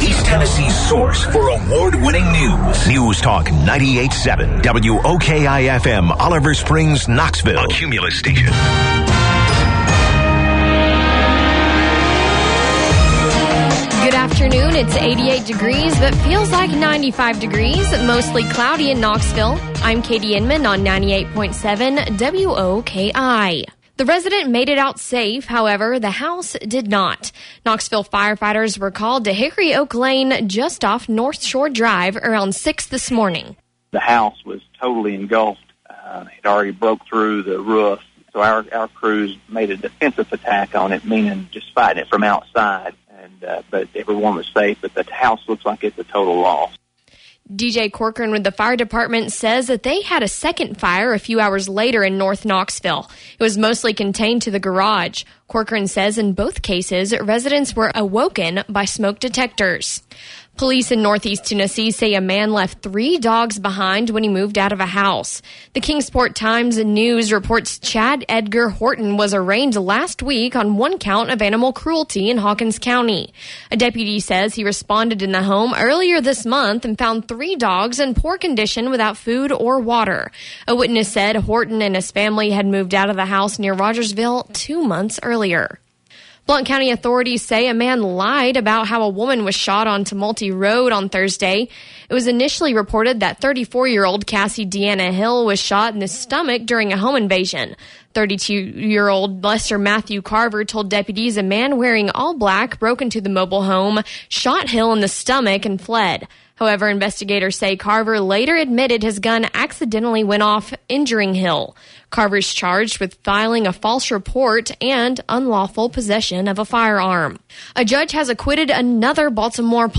1:00 Newscast– News Talk 98.7 WOKI {7.18.16}